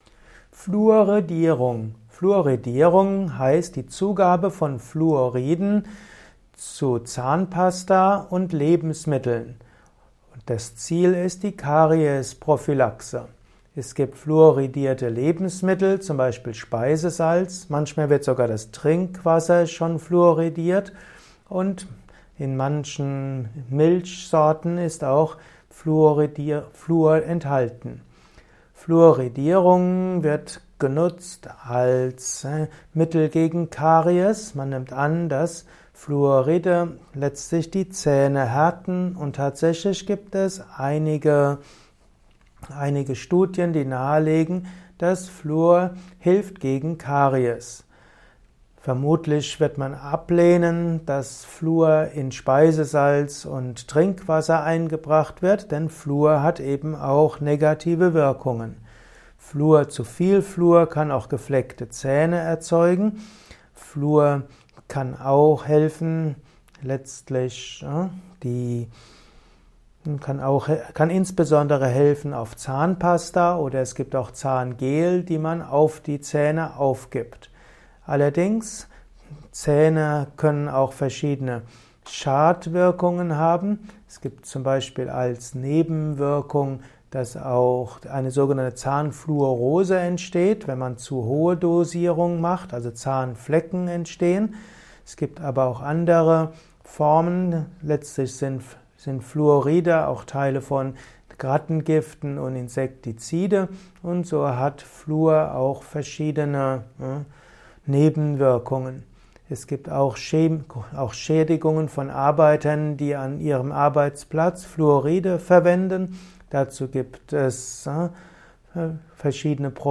Ein Kurzvortrag über die Fluoridierung